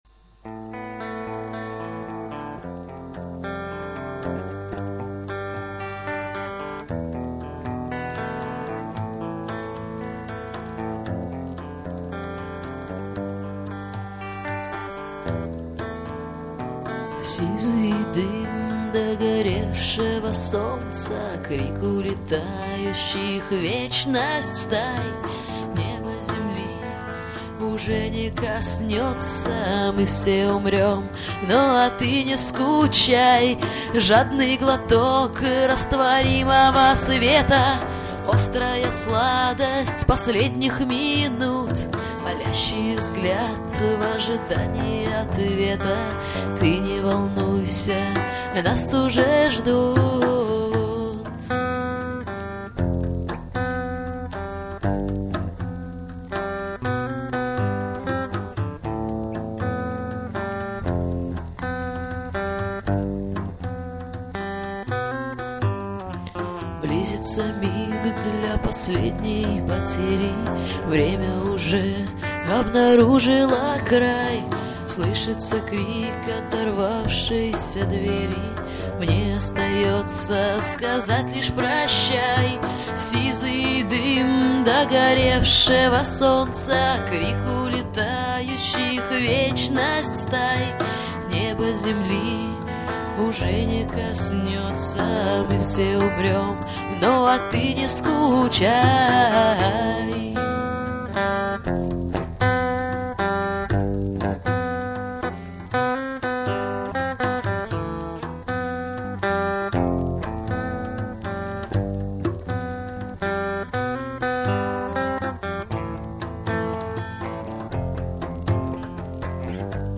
гитара
басс
перкуссия
Записано живьем в одно из февральских утр 2003.